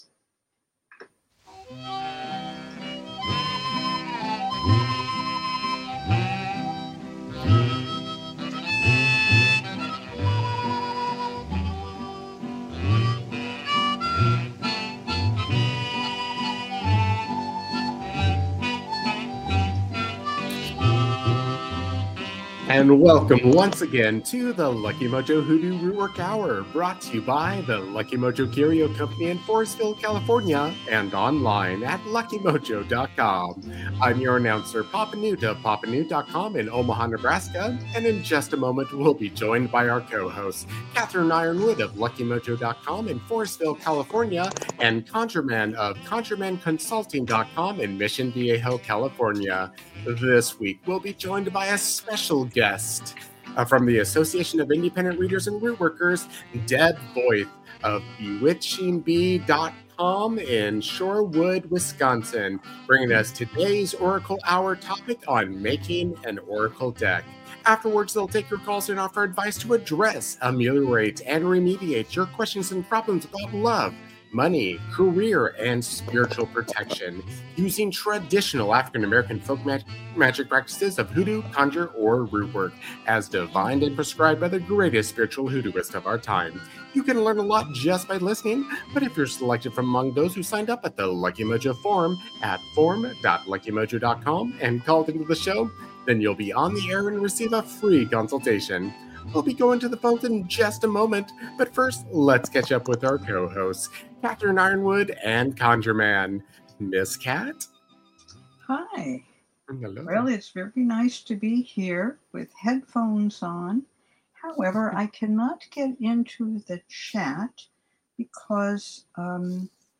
They provide free spells, free readings, and conjure consultations, giving listeners an education in divination and African-American folk magic.